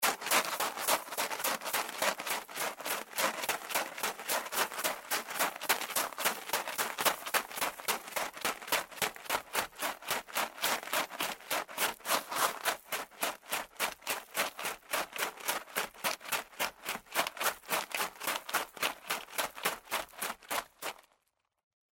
SFX下雪雪地上的跑步声2音效下载
SFX音效